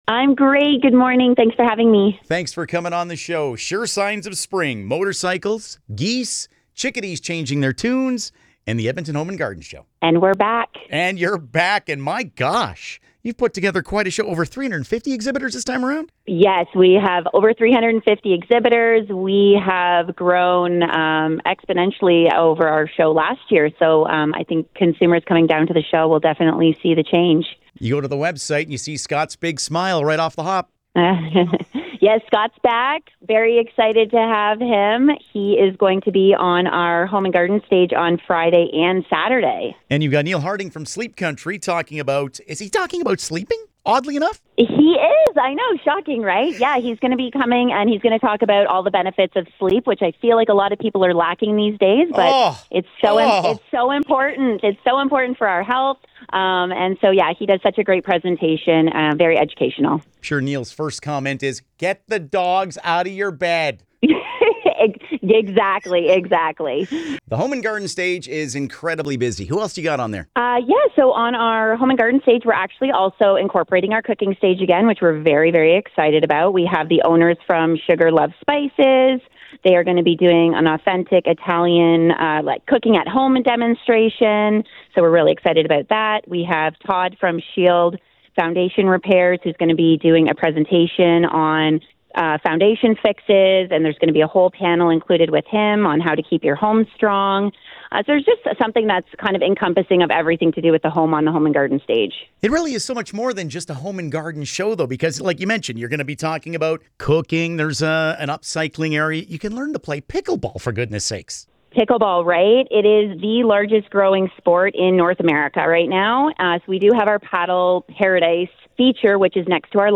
edm-home-and-garden-int.mp3